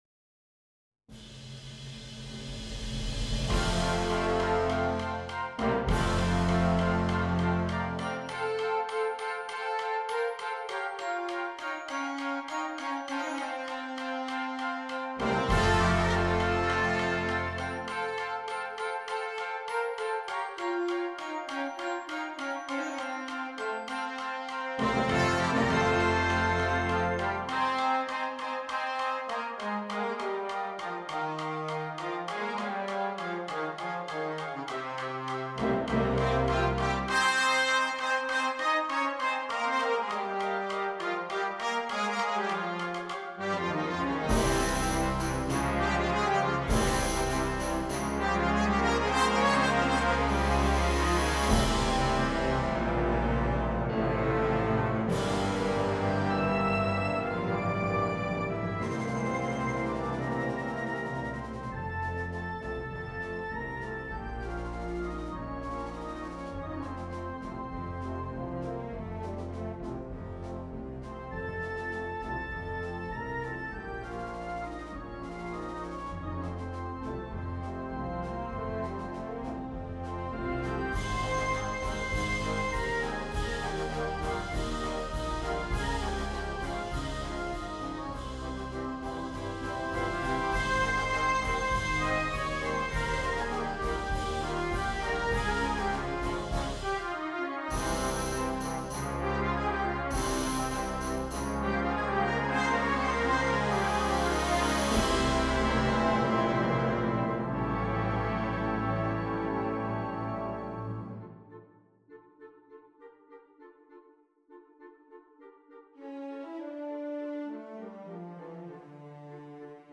Pasodoble de concierto